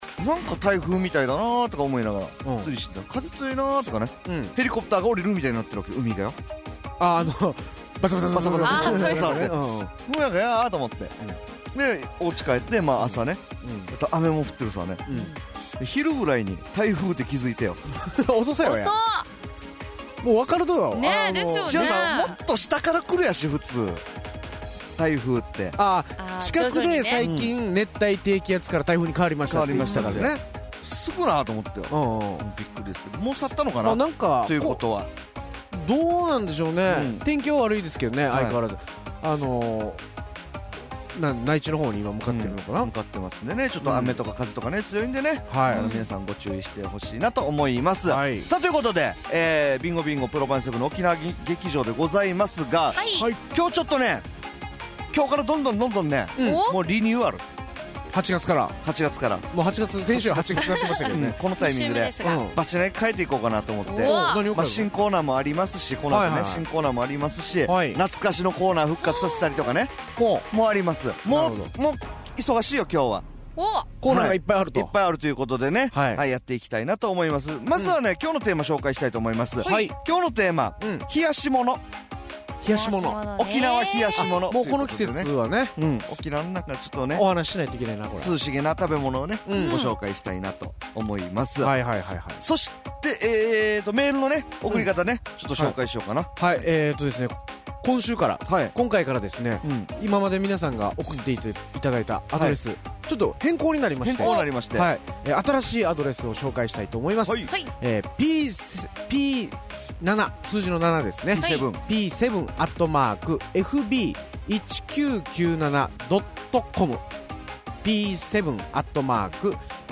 毎週月曜２３時から１時間 生放送。